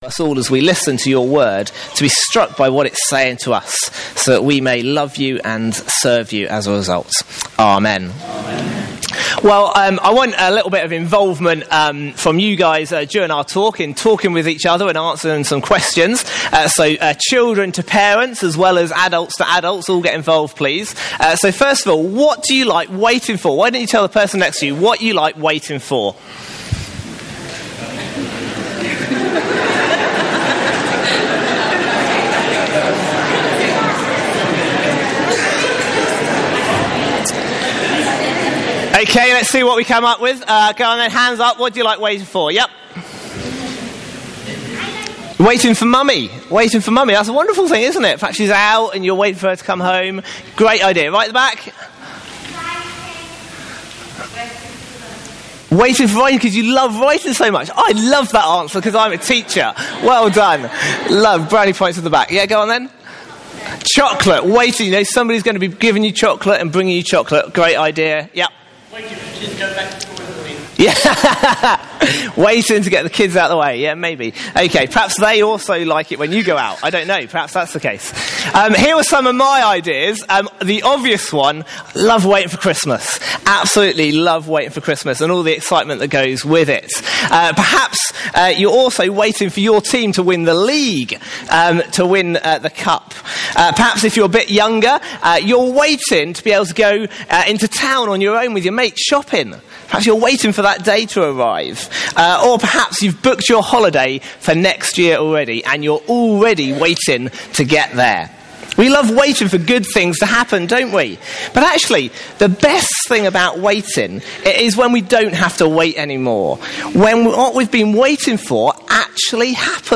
Sermons Archive - Page 92 of 188 - All Saints Preston